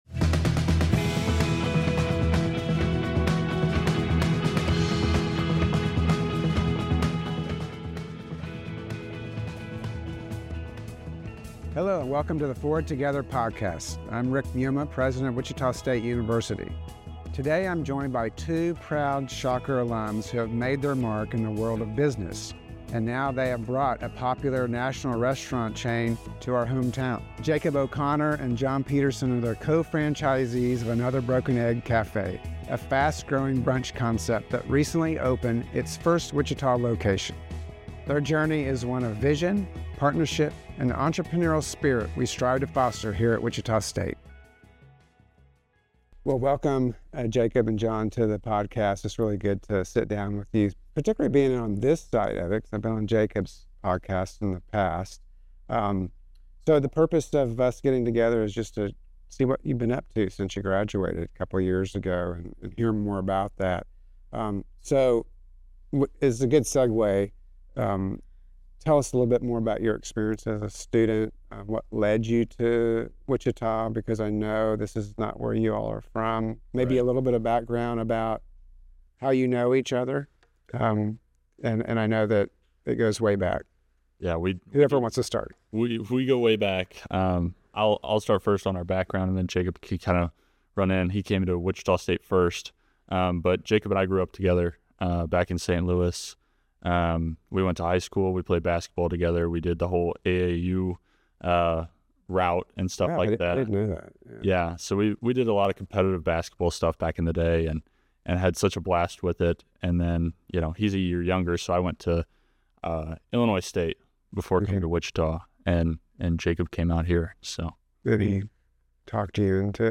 The “Forward Together” podcast celebrates the vision and mission of Wichita State University. In each episode, President Rick Muma will talk with guests from throughout Shocker Nation to highlight the people and priorities that guide WSU on its road to becoming an essential educational, cultural, and economic driver for Kansas and the greater good.